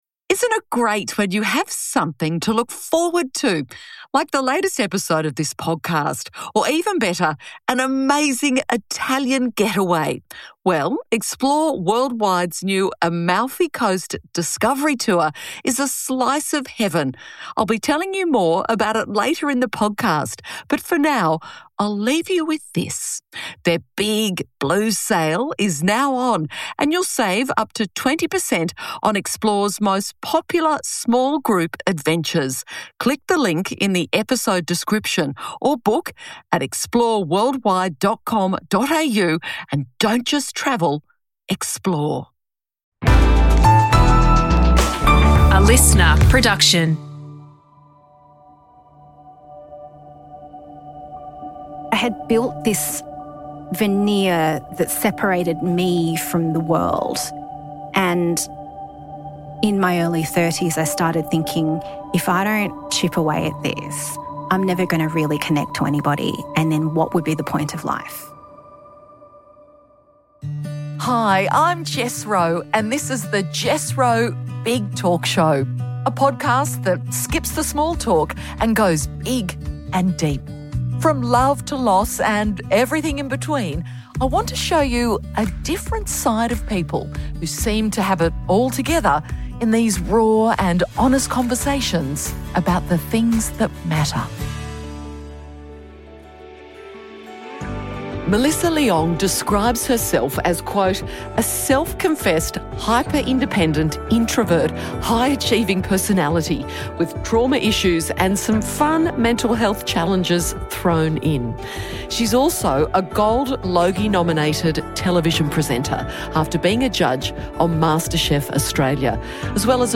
Host: Jessica Rowe Guest: Melissa Leong